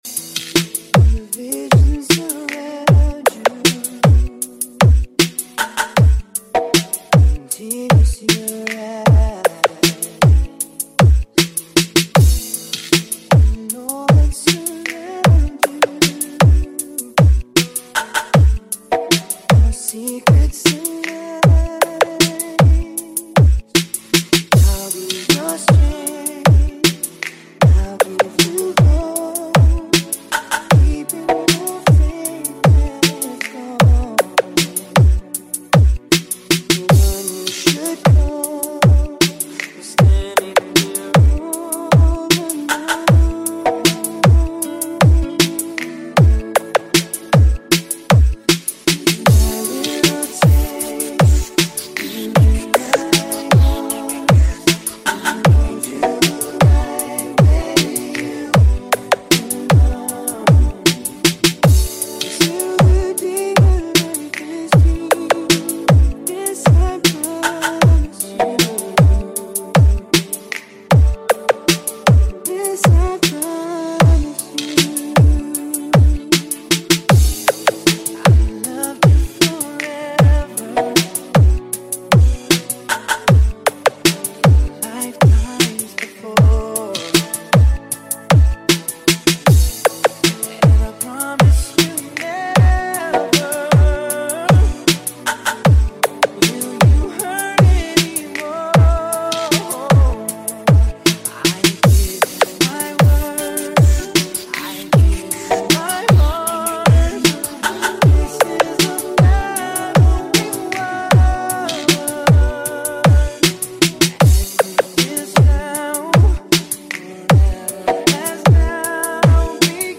Slowjam Remix